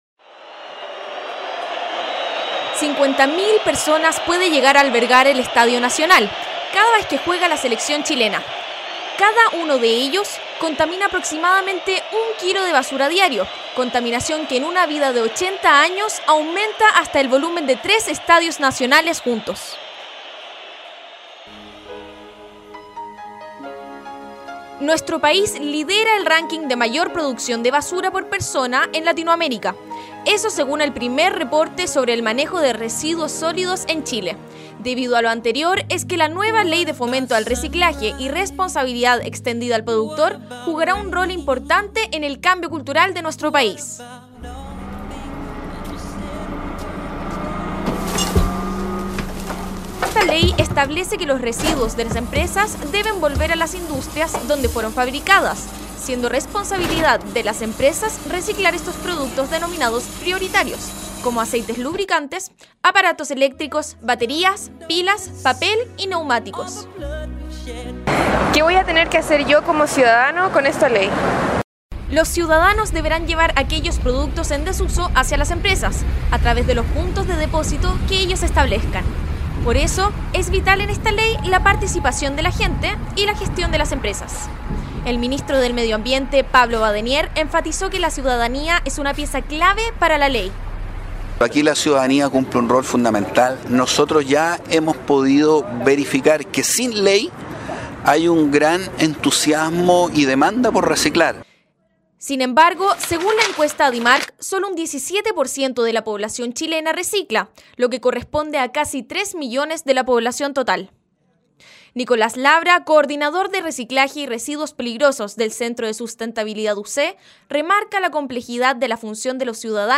Reportaje: «¿Funcionará la primera ley de reciclaje?»